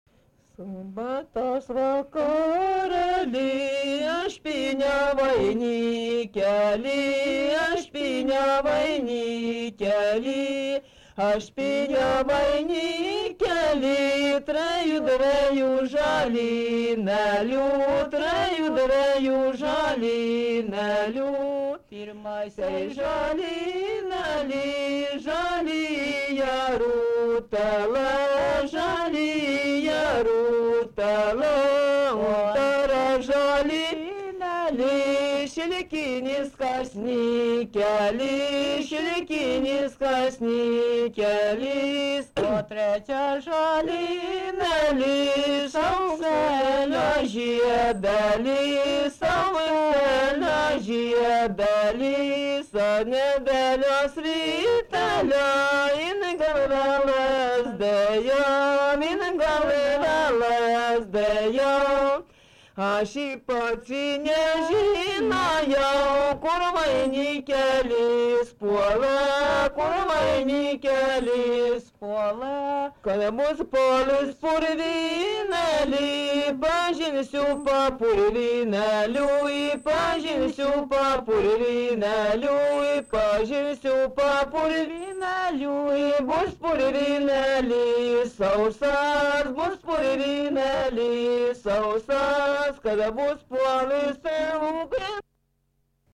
daina, vestuvių